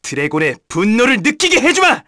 Neraxis-Vox_Skill4_kr.wav